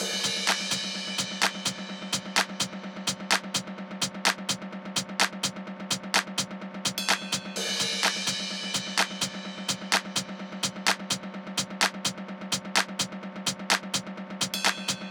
beat beige ultra break.wav